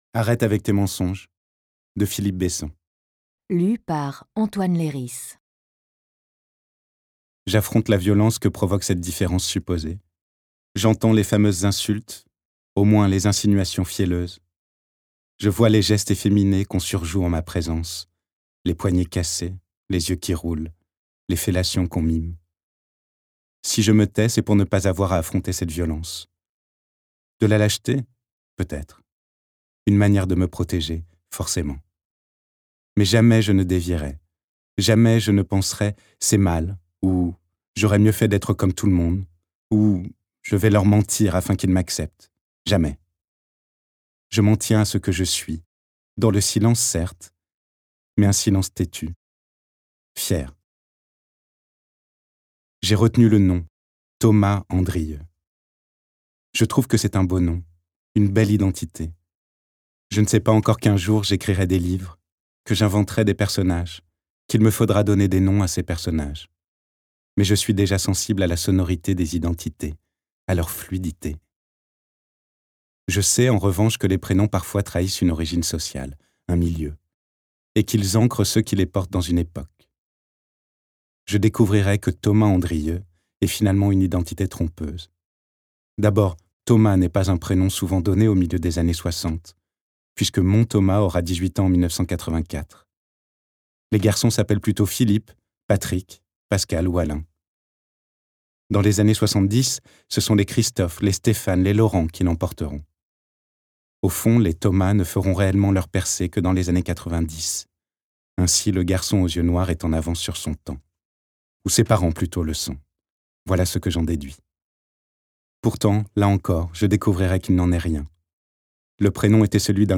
Prix Audiolib/Roman
Lu par Antoine Leiris
« Arrête avec tes mensonges » de Philippe Besson (extrait, lu par Antoine Leiris)